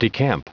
Prononciation du mot decamp en anglais (fichier audio)
Prononciation du mot : decamp